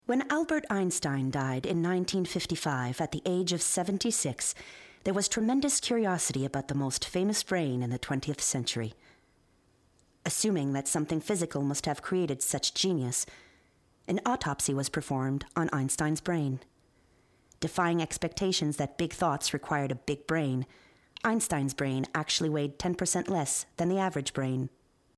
Narration 1 - ANG